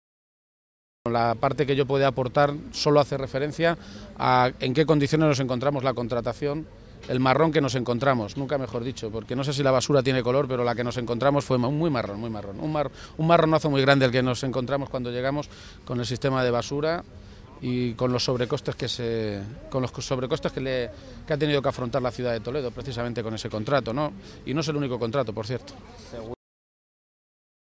García-Page se pronunciaba de esta maneta esta mañana, en Toledo, a peguntas de los medios de comunicación, horas antes de que declare como testigo en la Audiencia Nacional en el marco de las investigaciones de los llamados “papeles de Bárcenas”.
Cortes de audio de la rueda de prensa